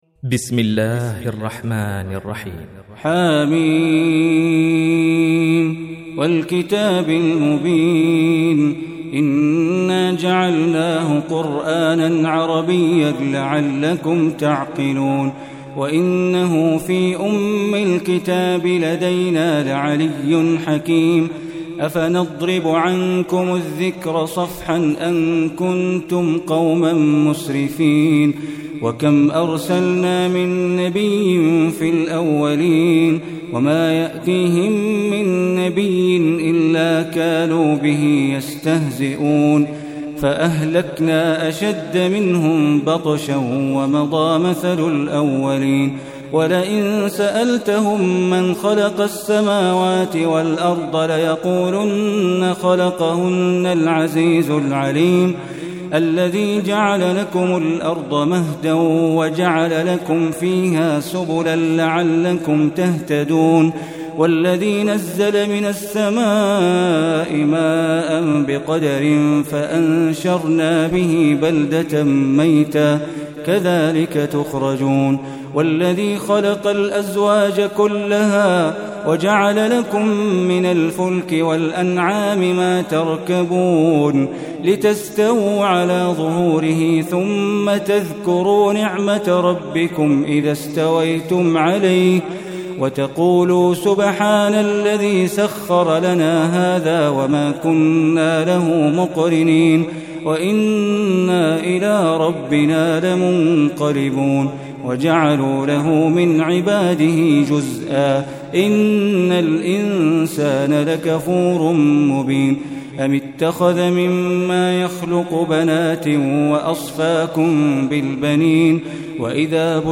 Surah Az-Zukhruf Recitation by Bandar Baleela